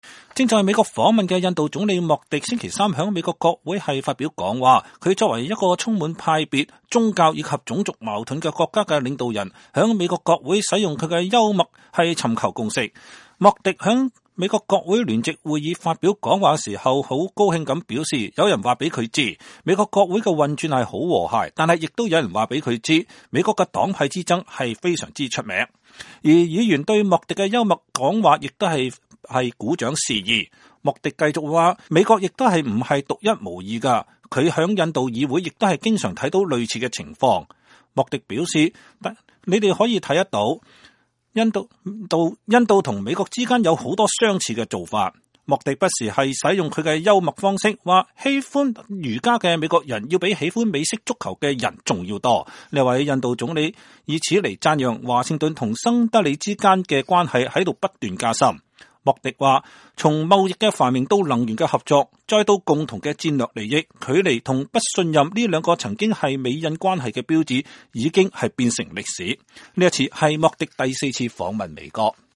印度總理在美國國會演講 瀟灑幽默
正在美國訪問的印度總理莫迪週三在美國國會發表講話。他作為一個充滿派別、宗教和種族矛盾的國家的領導人在美國國會使用他的幽默尋求共識。